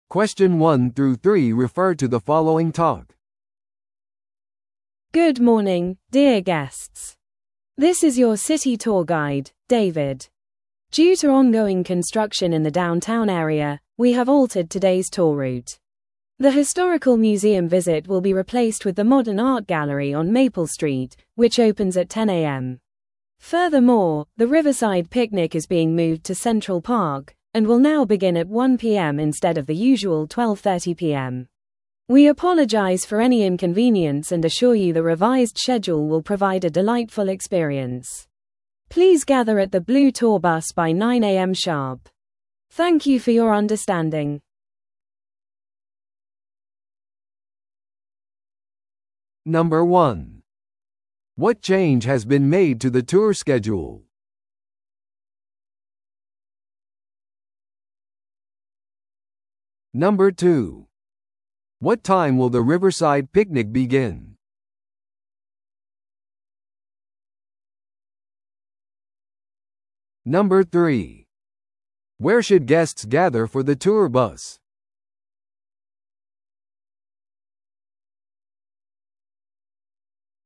TOEICⓇ対策 Part4｜観光ルートの変更案内 – 音声付き No.017